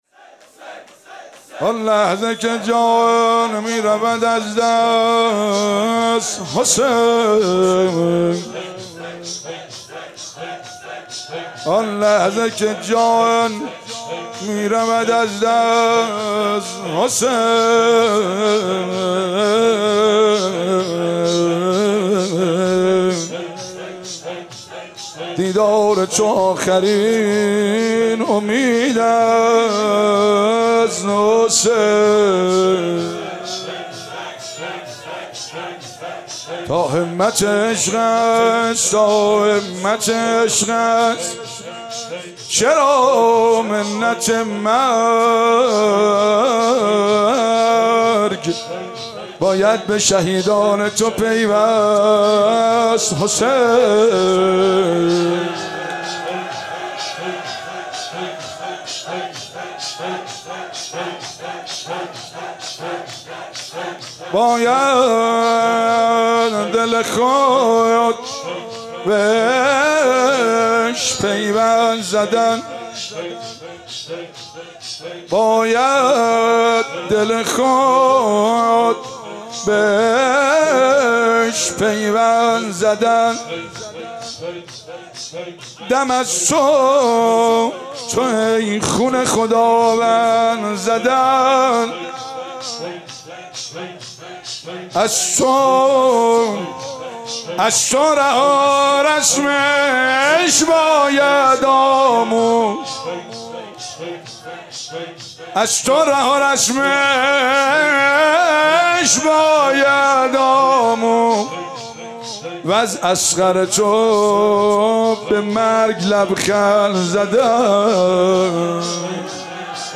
شب هفتم محرم95/هیئت فاطمیون قم(مسجد مقدس جمکران)
زمزمه و شور/آن لحظه که جان می رود